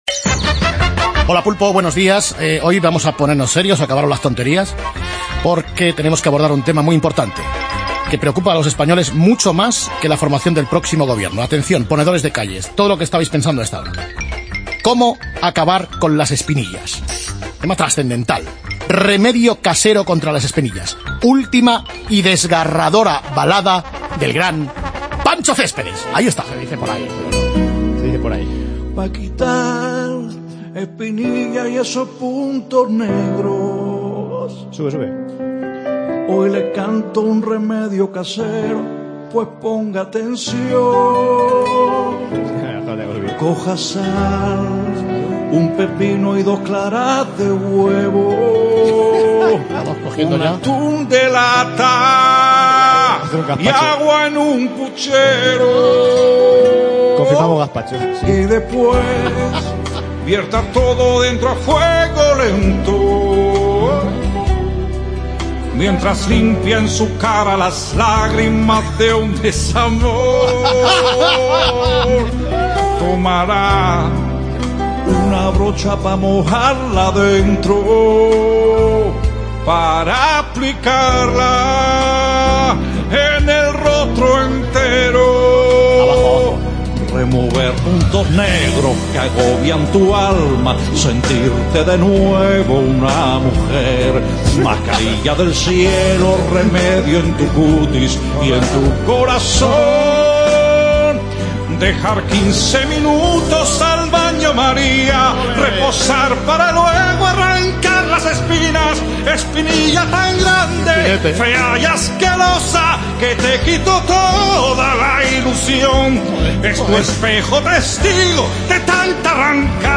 Pancho Céspedes canta un remedio para las espinillas